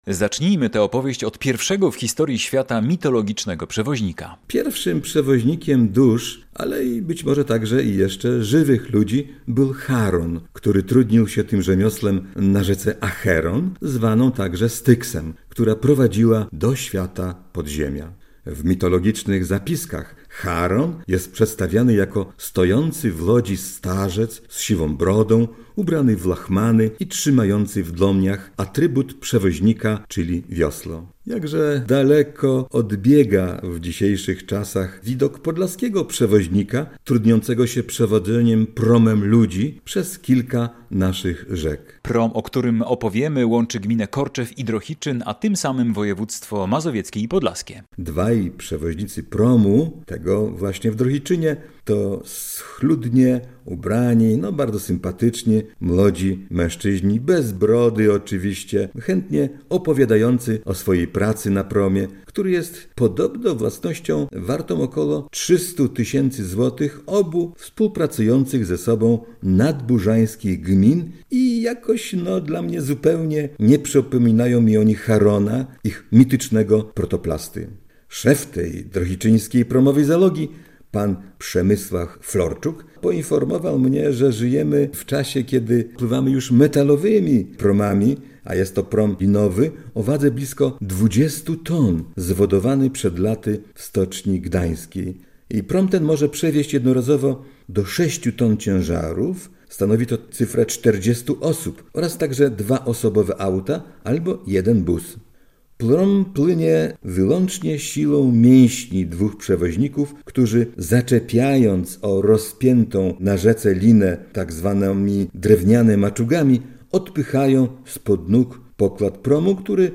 A w niemal bezszelestny rejs promem po Bugu udajemy się teraz